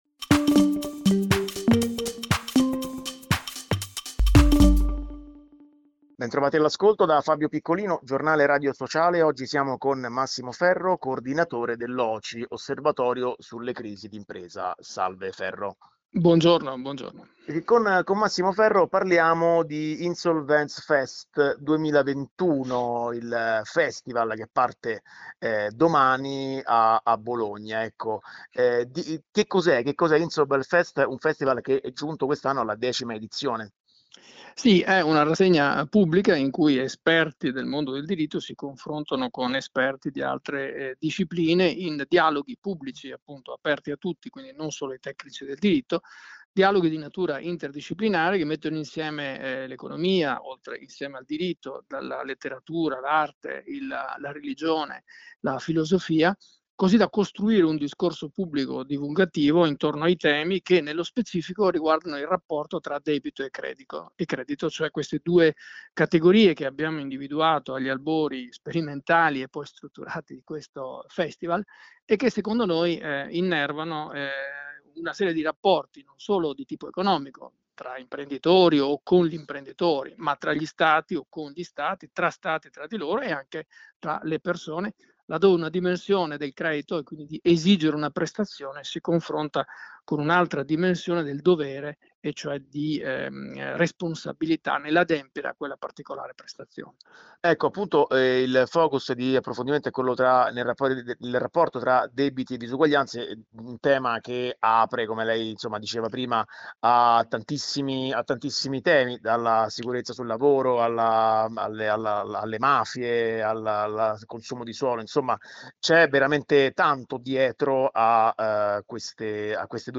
Debiti e disuguaglianze: torna InsolvenzFest. Intervista